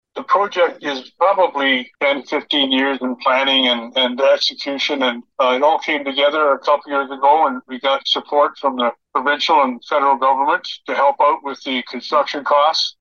Trent Hills Mayor Bob Crate told us it’s been a long time coming.